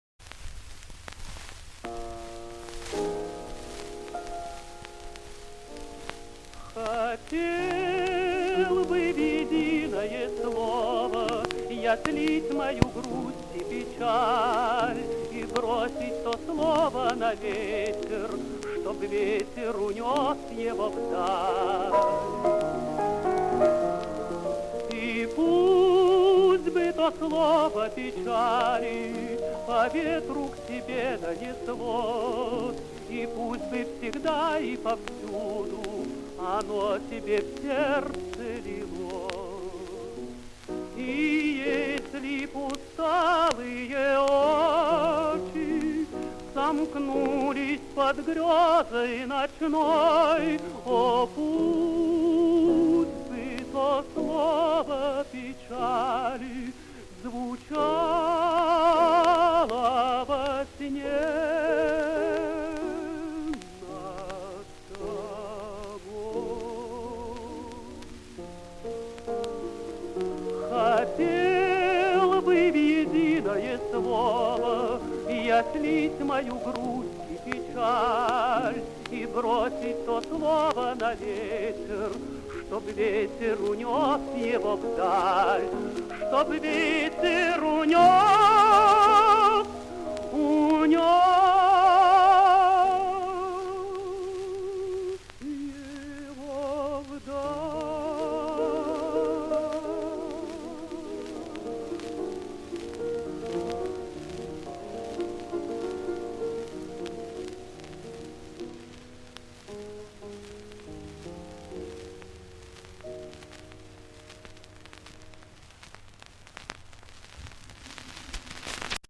Обладал красивым, «полётным», свободно звучащим голосом, особенно в верхнем регистре.
Романс «Хотел бы в единое слово». Исполняет И. Д. Жадан.